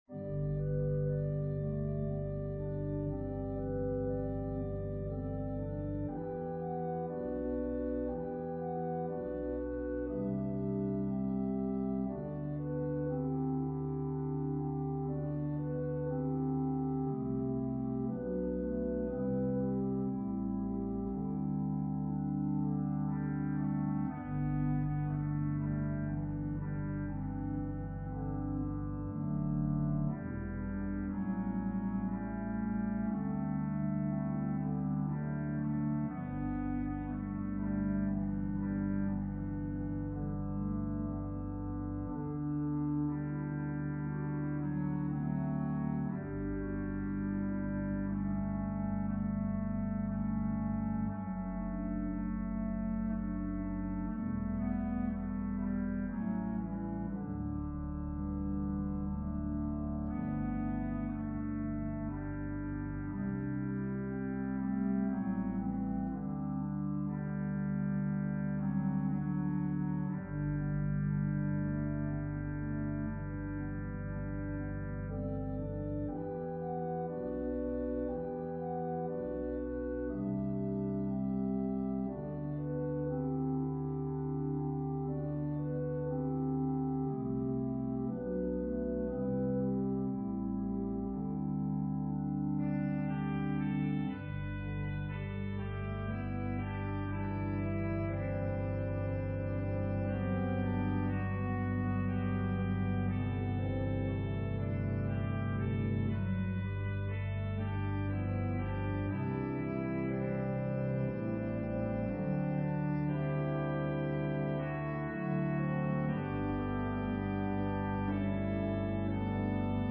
An organ solo version
Voicing/Instrumentation: Organ/Organ Accompaniment